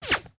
Whew.ogg